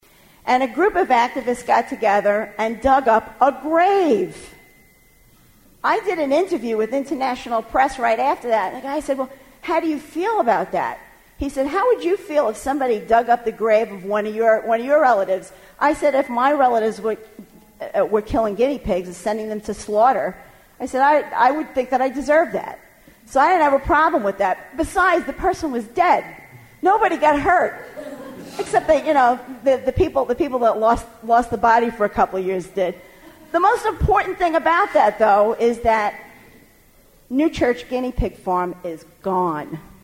Audio from official conference recordings]